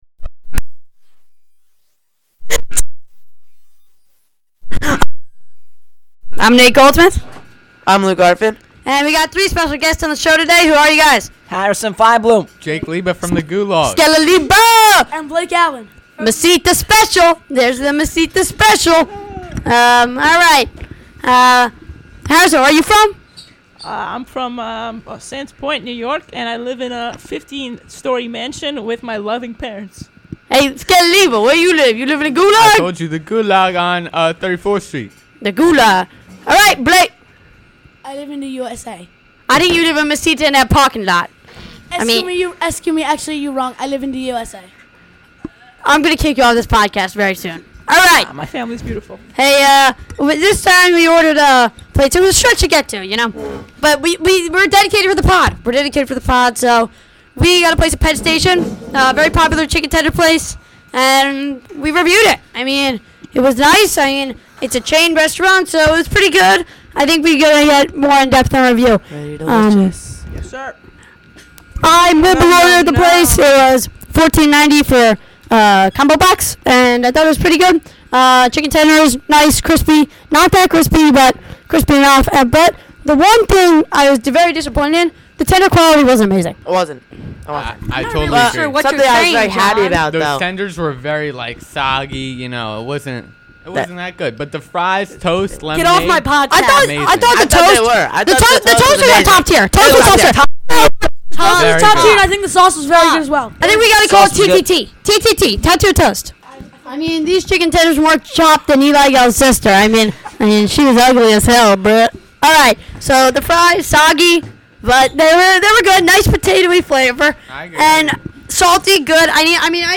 Music used is incidental or background clips, in accordance of 37 CFR 380.2. of the US Copyright Law.